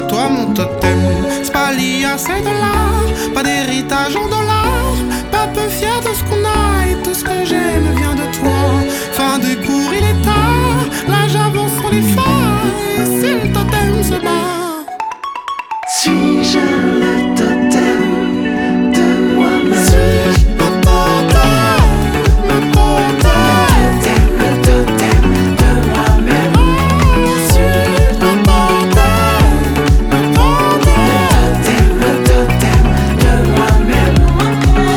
French Pop
Жанр: Поп музыка